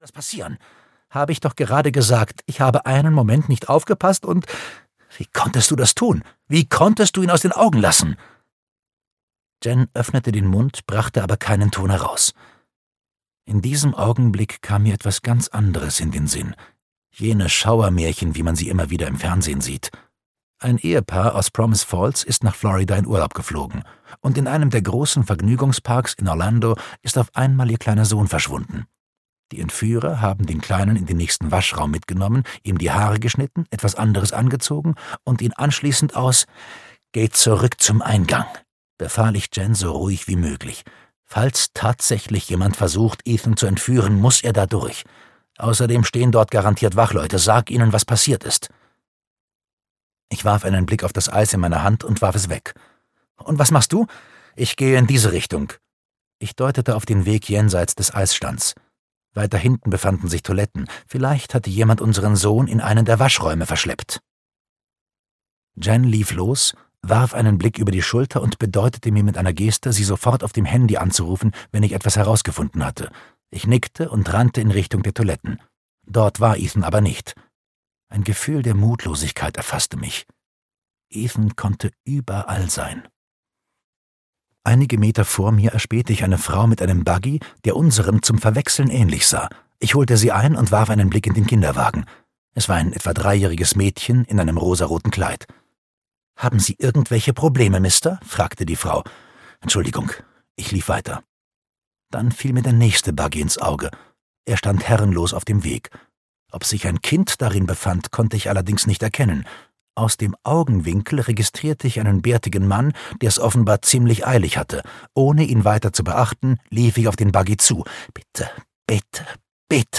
Kein Entkommen - Linwood Barclay - Hörbuch